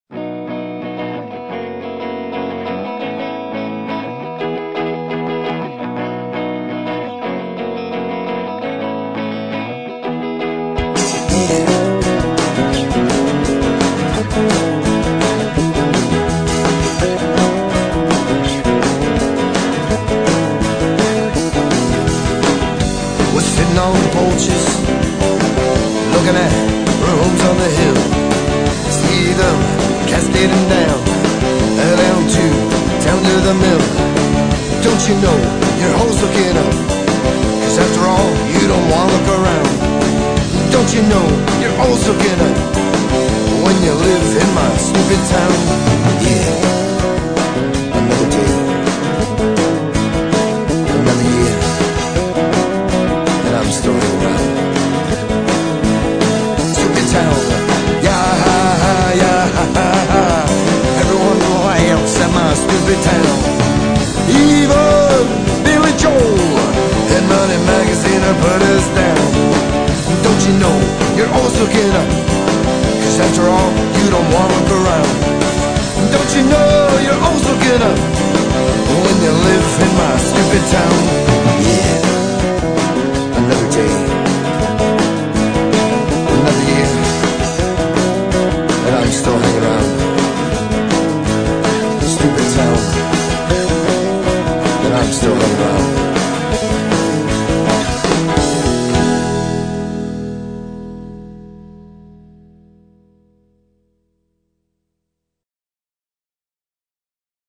punk rock, psychedelic
Psychedelic punk rock dance music from Bethlehem.